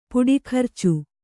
♪ puḍi kharcu